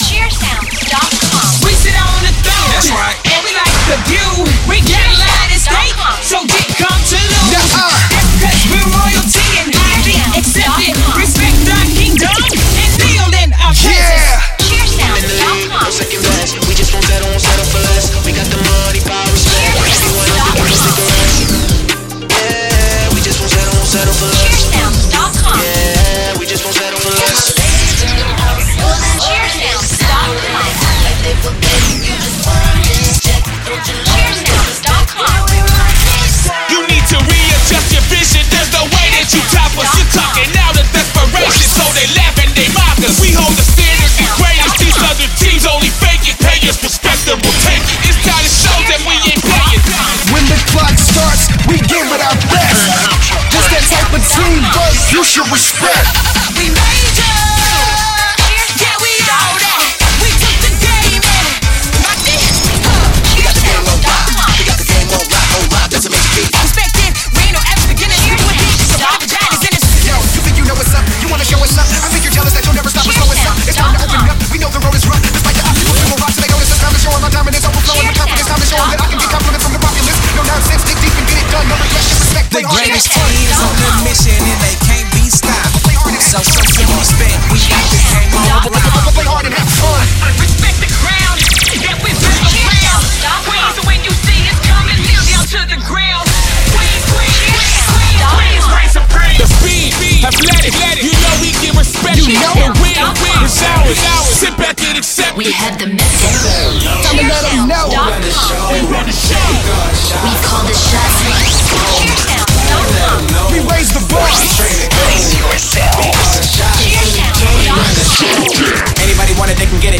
Premade Cheer Music Mix